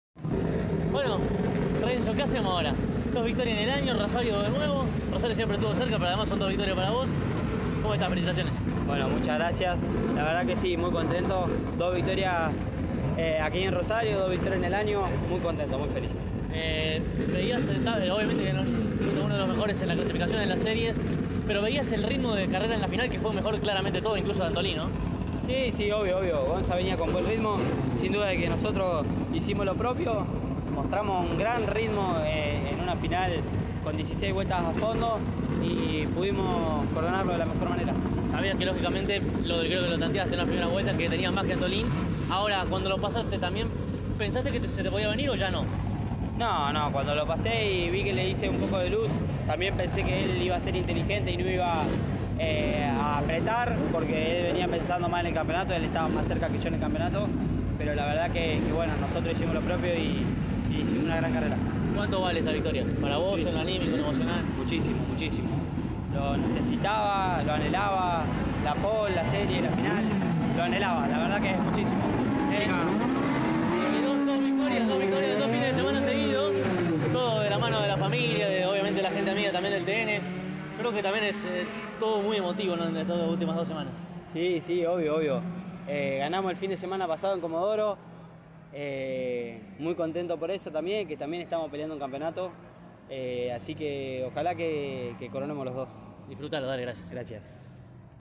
Este fin de semana, el Turismo Nacional disputó su décima fecha de la temporada y la primera de las dos finales en disputarse del domingo fue la de la Clase 2. Tras ella, los integrantes del podio, dialogaron con CÓRDOBA COMPETICIÓN.